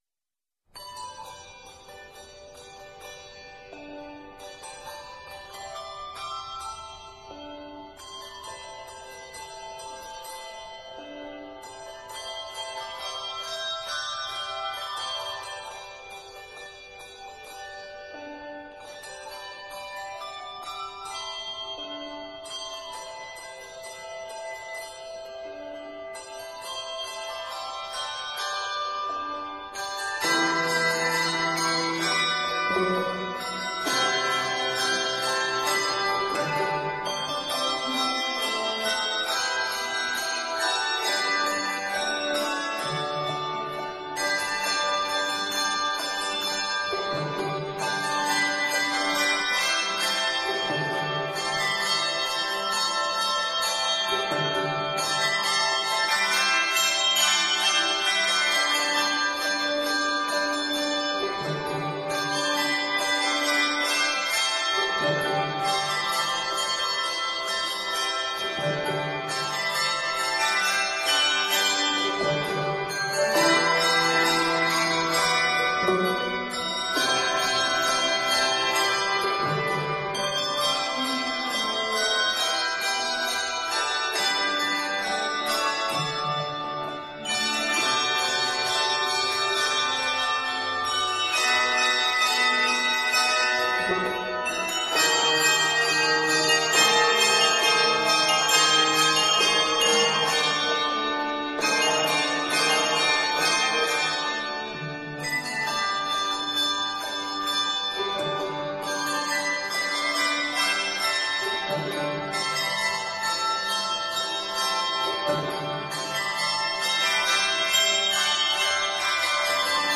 3-octaves and 4, 5 or 6-octaves.